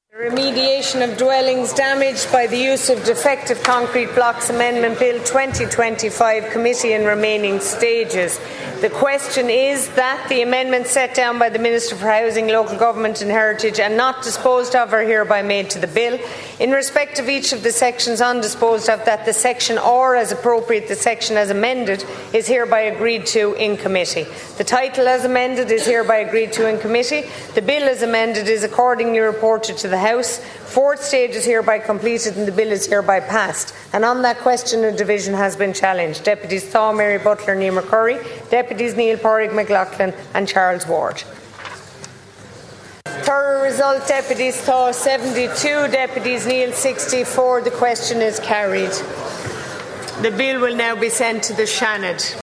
After dozens of opposition amendments were voted on and lost, Ceann Comhairle Verona Murphy confirmed the vote had been passed….